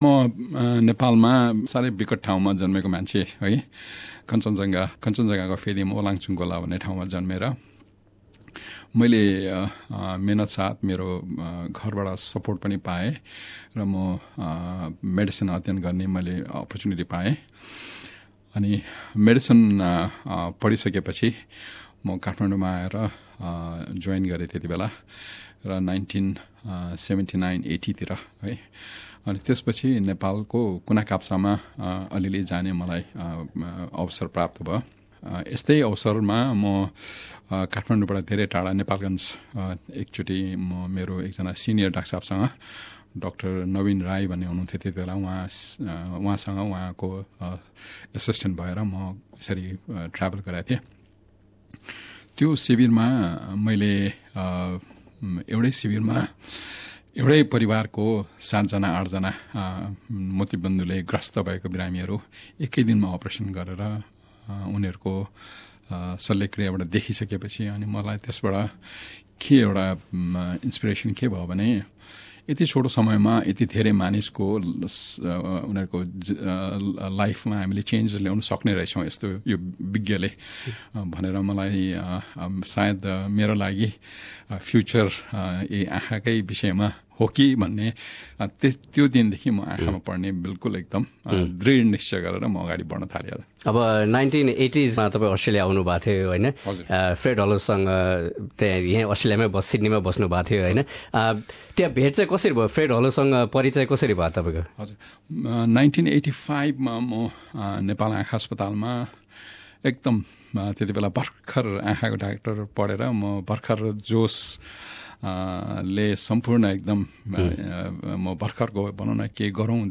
Dr Sanduk Ruit speaking to SBS Nepali on 1 Sept 2017.